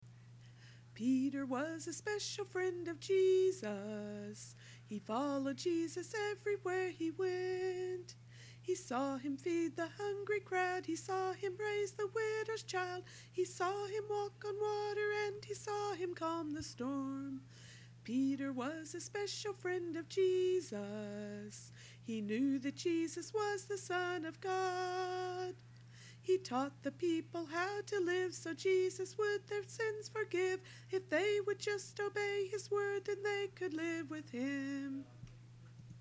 A song for young children.